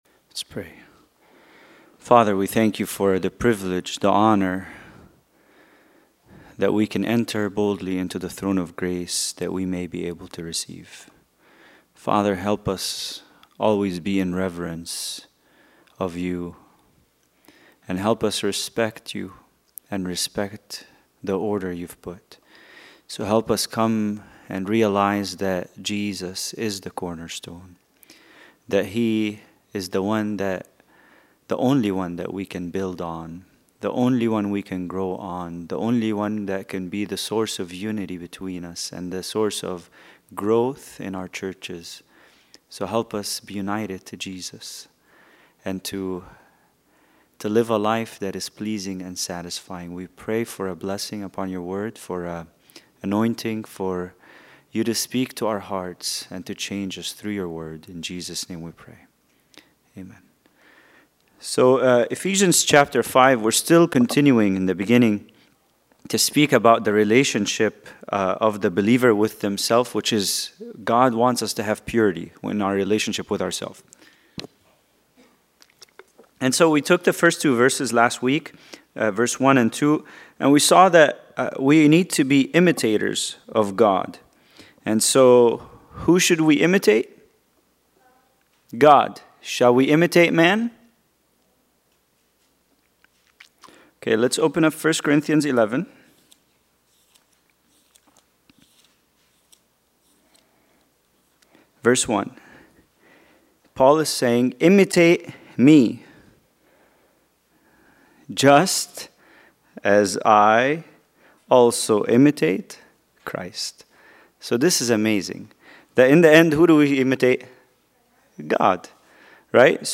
Bible Study: Ephesians 5:3-6